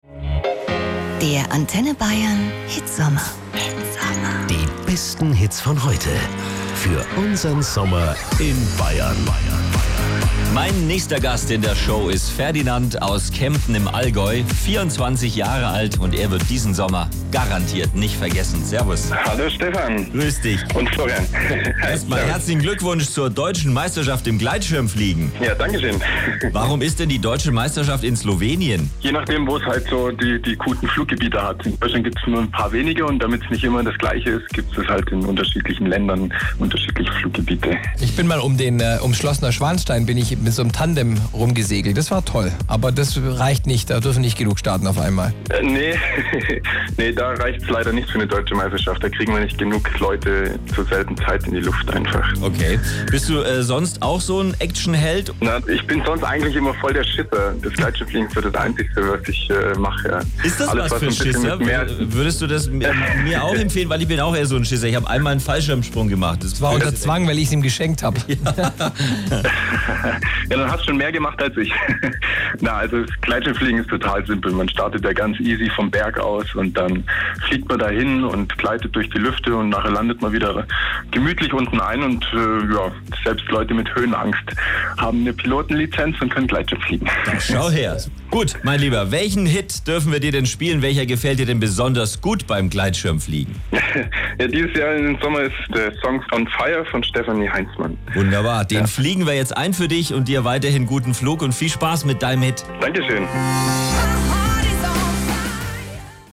Interview mit Antenne Bayern
Heute gab es ein Telefoninterview mit den Moderatoren von Antenne Bayern.